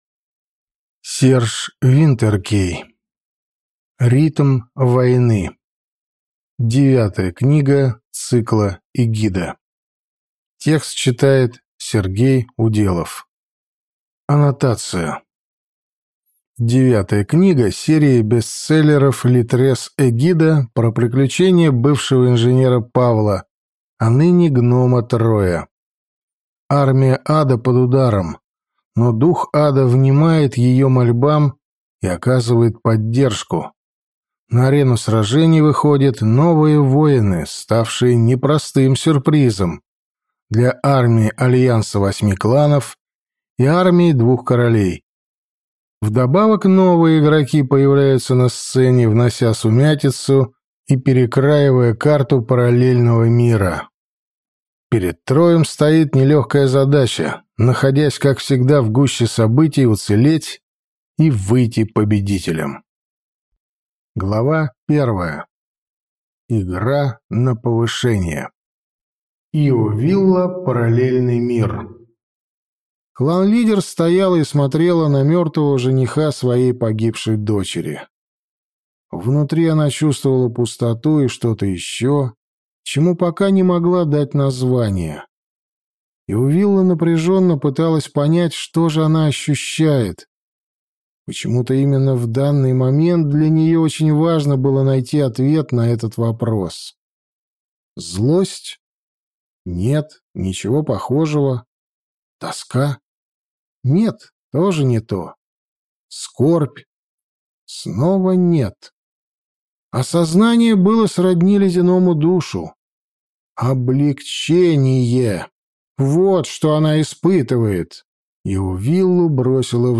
Аудиокнига Ритм войны | Библиотека аудиокниг